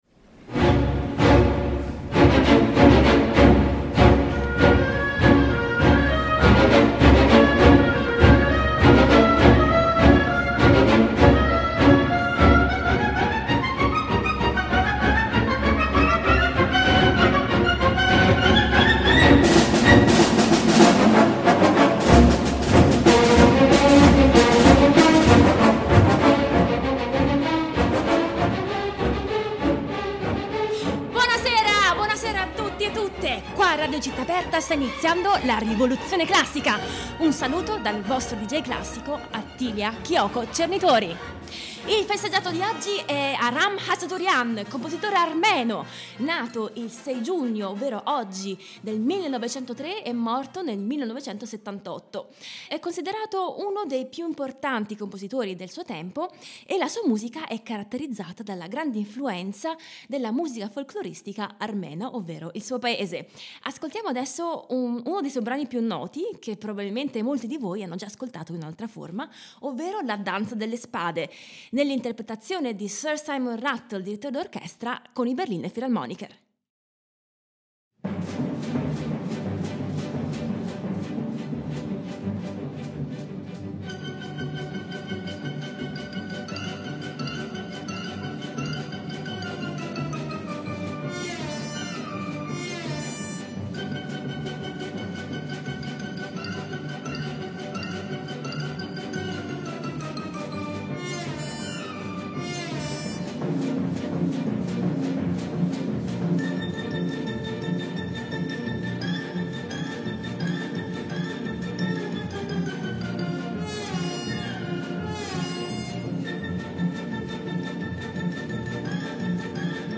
Ospite di questa puntata il clarinettista Alessandro Carbonare